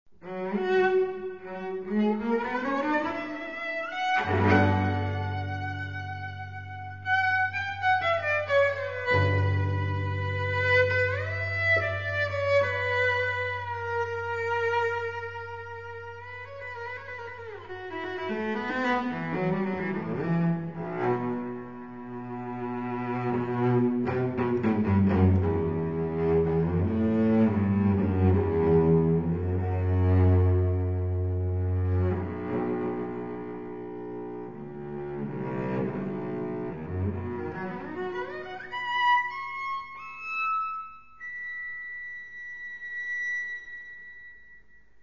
violoncelle seul